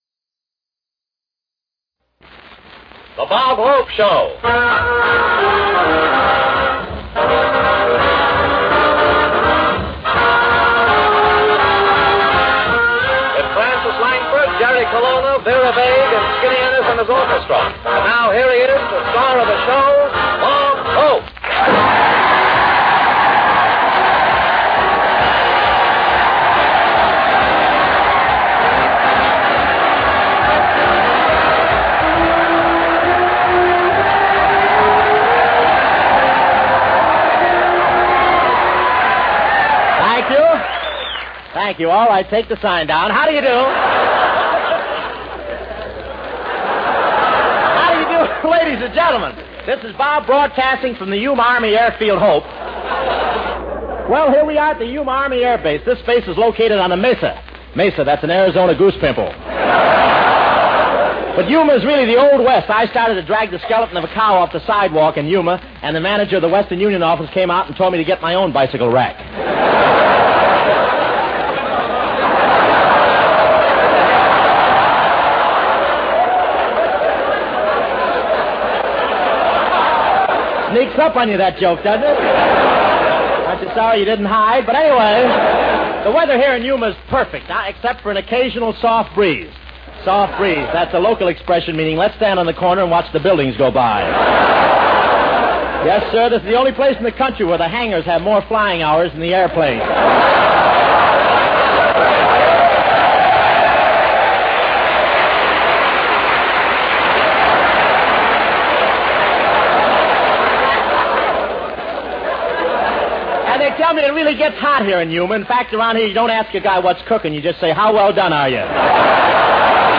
OTR Christmas Shows - From Yuma Army Base, Arizona - Andrew Sisters then Lionel Hampton remote - 1945-01-02 AFRS Bob Hope